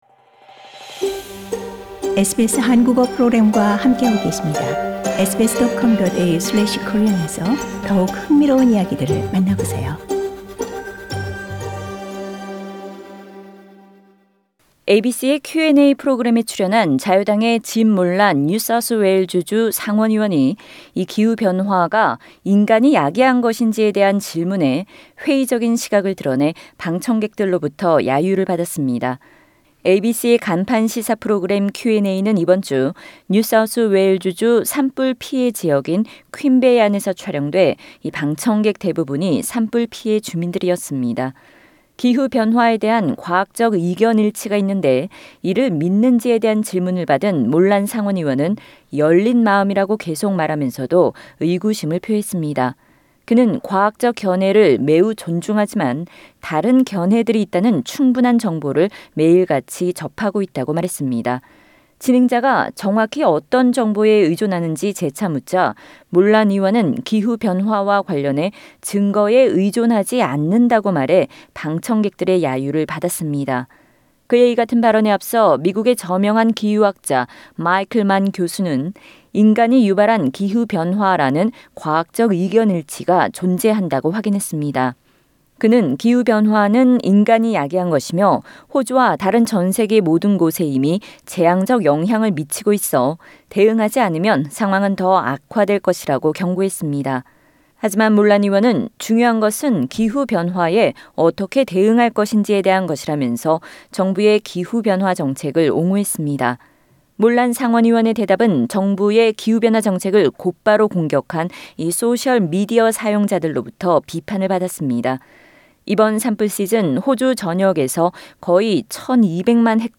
The Liberal senator was responding to audience questions from community members of the fire-affected NSW city of Queanbeyan.
Senator Jim Molan on ABC's QandA show.
New South Wales Liberal senator Jim Molan has been heckled by members of the ABC's Q&A audience when questioned about the basis for his doubts about human-induced climate change. Asked whether he believed the scientific consensus of climate change, Senator Molan said he is keeping an "open mind" but does have doubts.